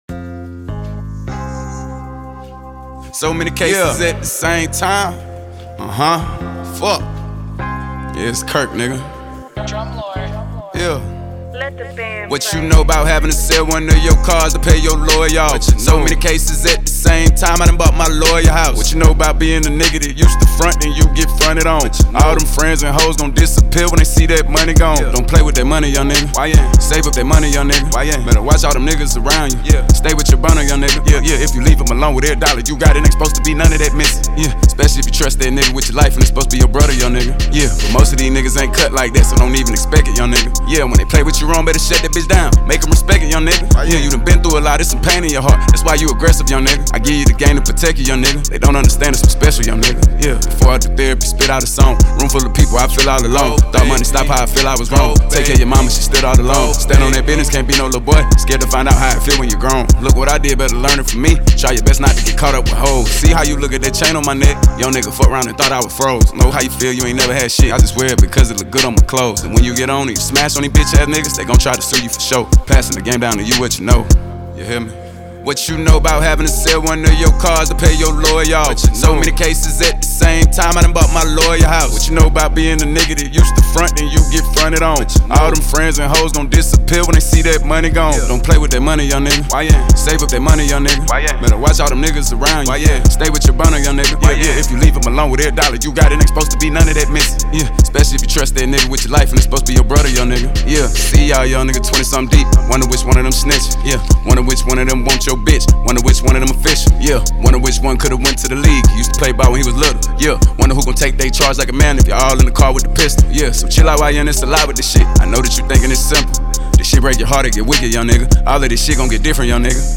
Трек размещён в разделе Рэп и хип-хоп / Зарубежная музыка.